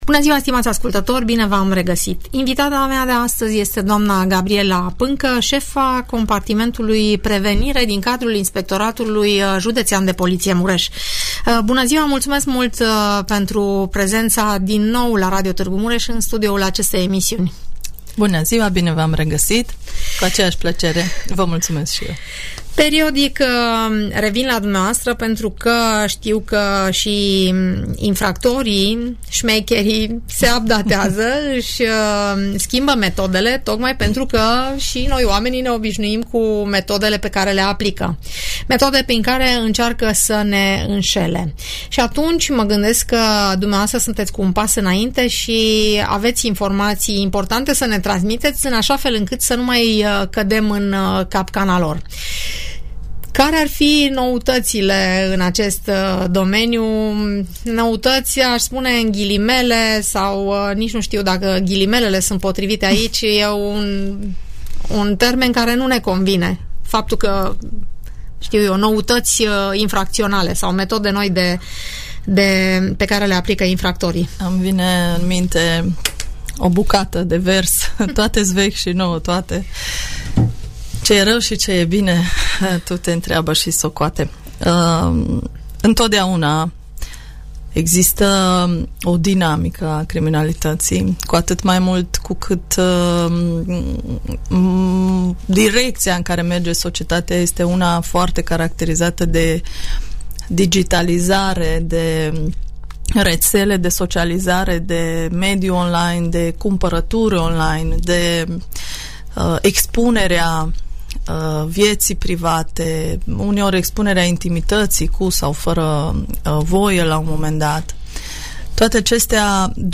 Acesta este subiectul discuției